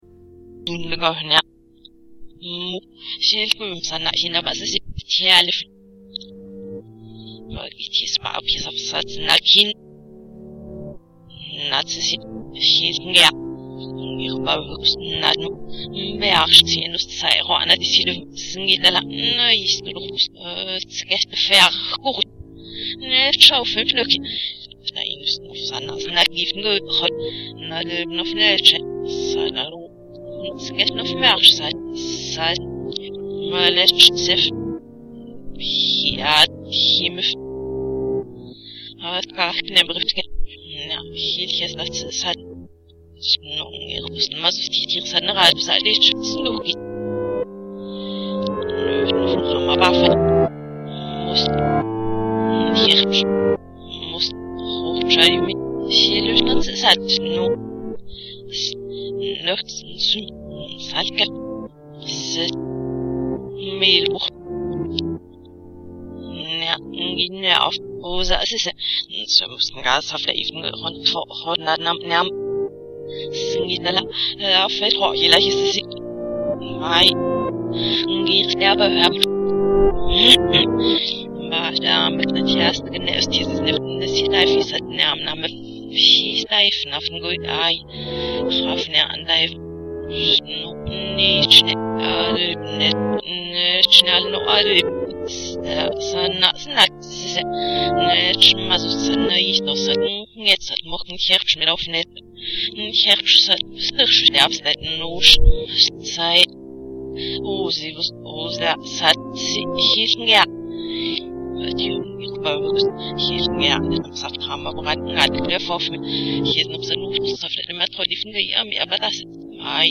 musik und text (mp3, 2,103 KB)
Rückwärts dieses:
Klangexperimente sind, vor allem in dieser Klang"qualität", kein Ohrenschmaus.